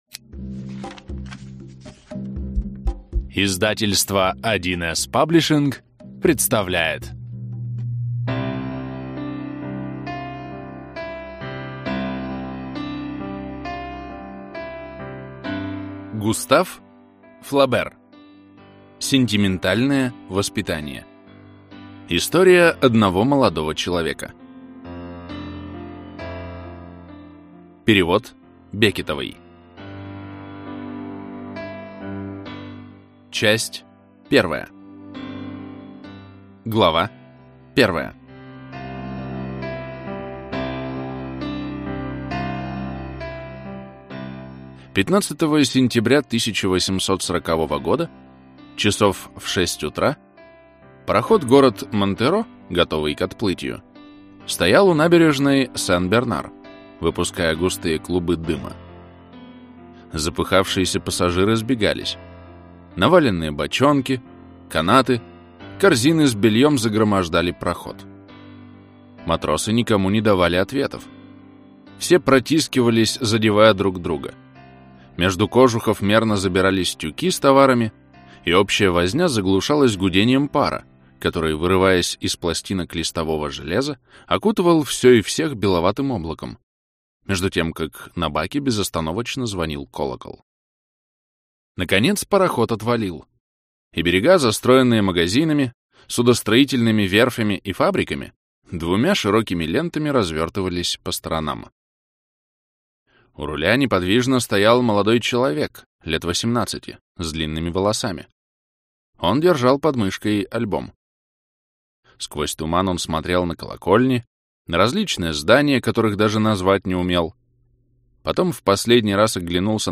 Аудиокнига Воспитание чувств - купить, скачать и слушать онлайн | КнигоПоиск